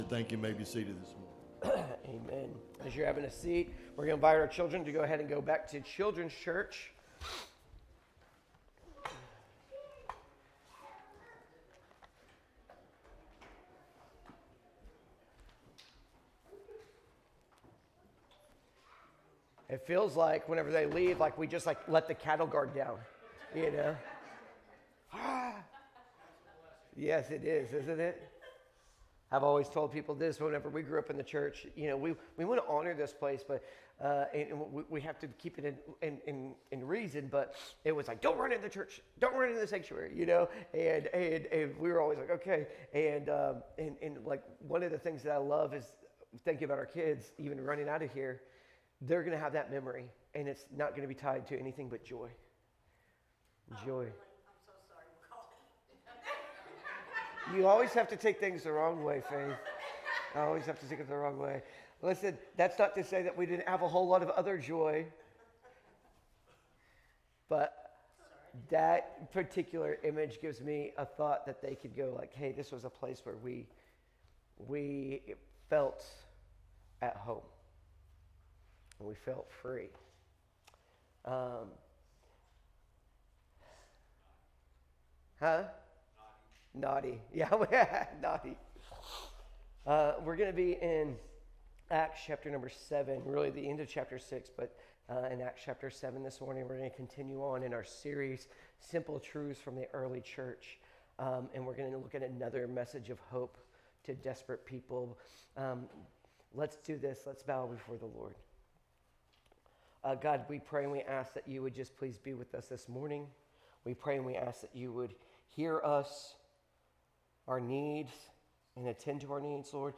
1 Sunday Service 33:34